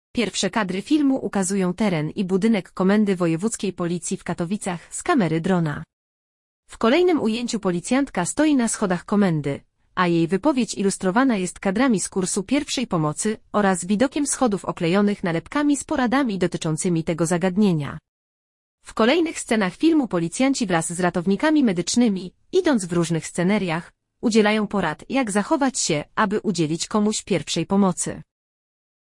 Nagranie audio Audiodeskrypcja_filmu.mp3